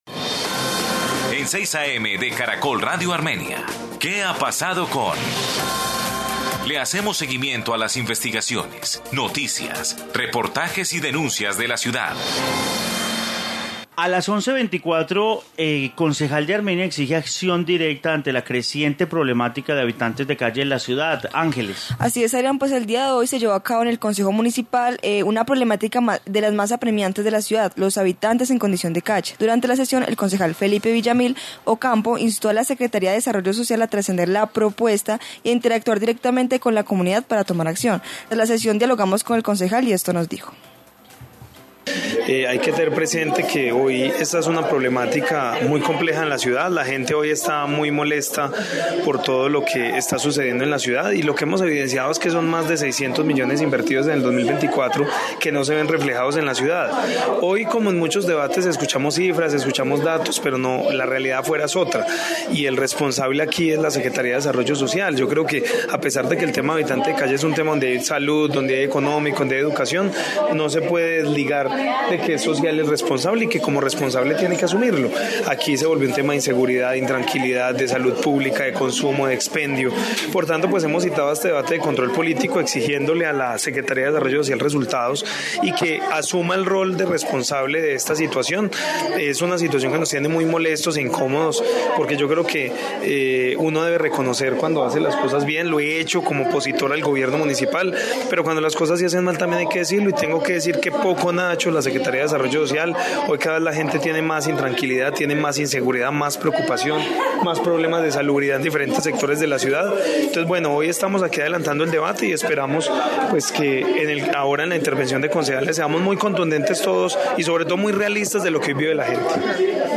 Informe sobre debate de habitante de calle en Armenia